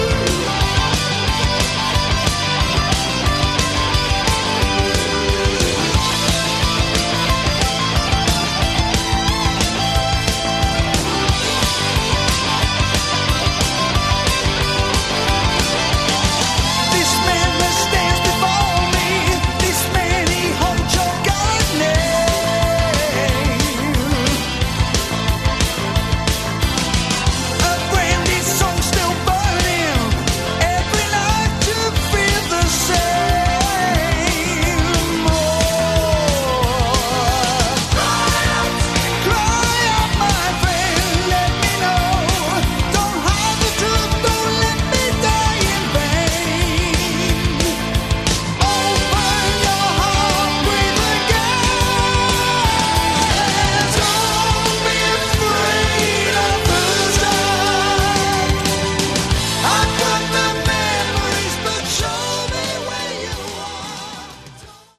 Category: Hard Rock
guitars, lead and backing vocals, keyboards
drums, percussion